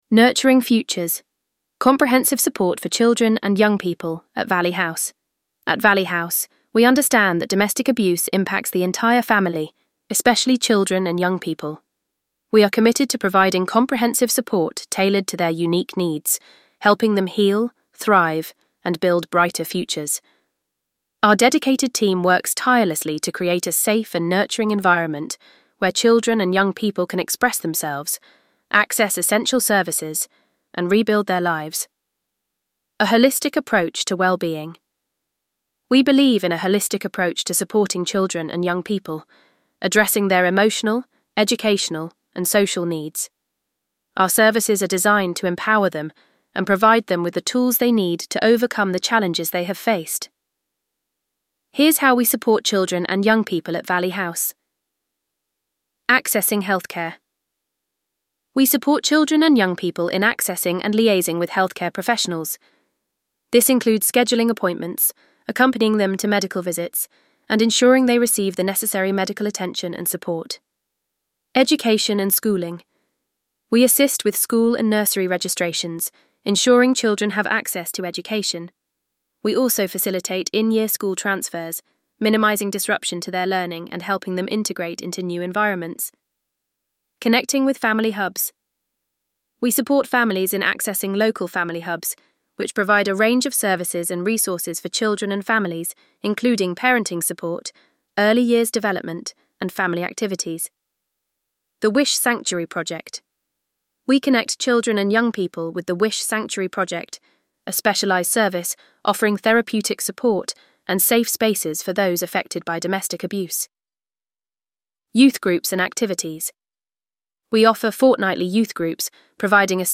VOICEOVER-Children-and-Young-people.mp3